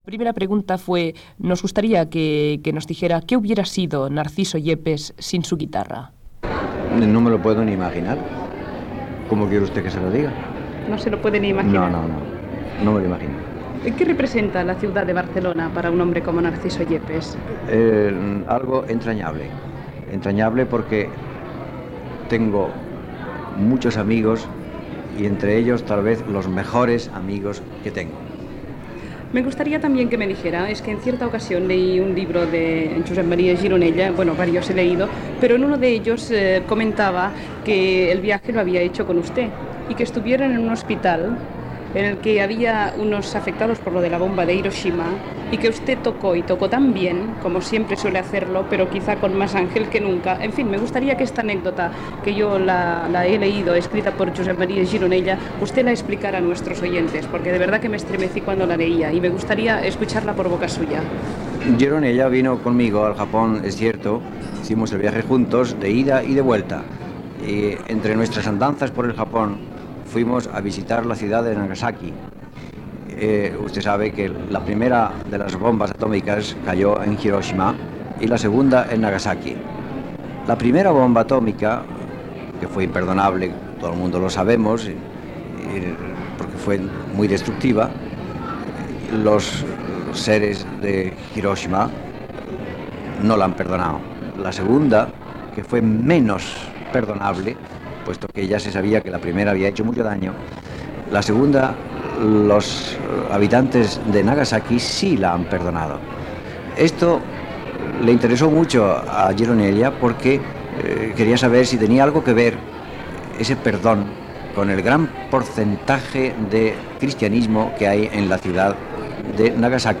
Entrevista al guitarrista Narciso Yepes feta a la ciutat de Barcelona. S'hi parla d'un concert que va fer al Japó i de Barcelona